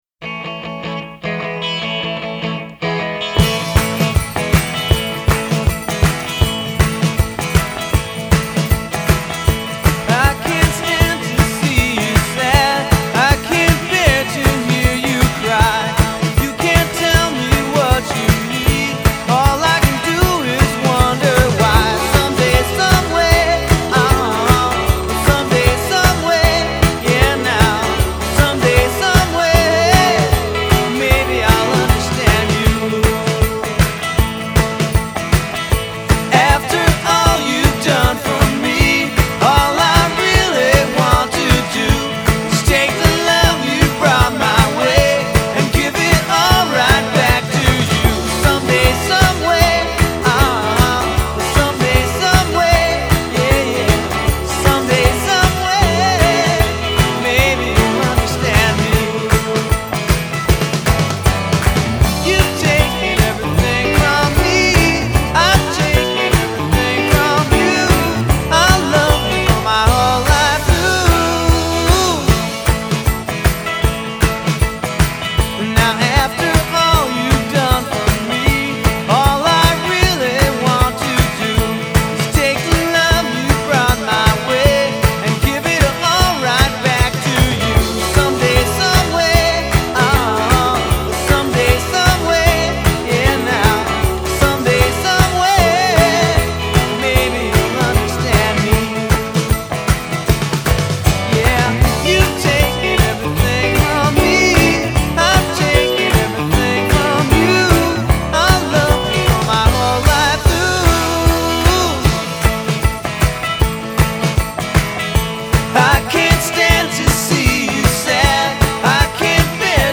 so please enjoy the vinyl rip that I have included below.